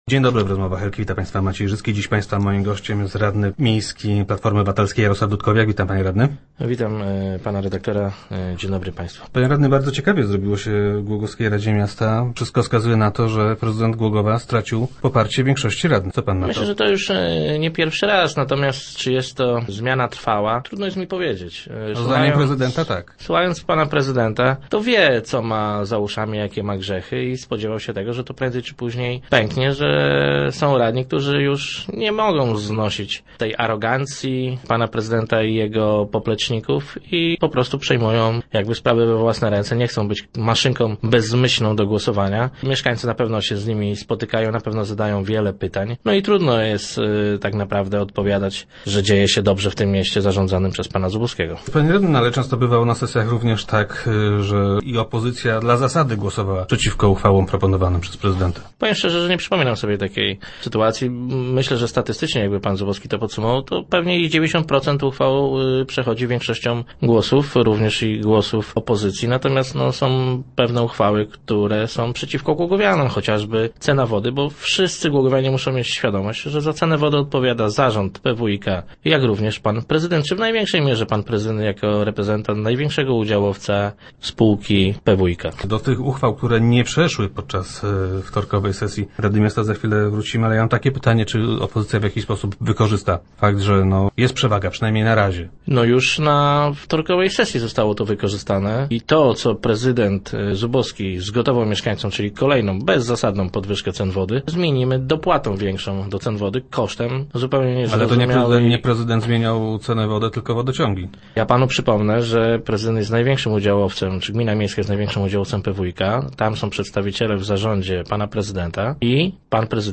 Jak zamierza ją wykorzystać? Gościem piątkowych Rozmów Elki był Jarosław Dudkowiak, radny klubu Platformy Obywatelskiej.